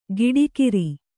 ♪ giḍikiri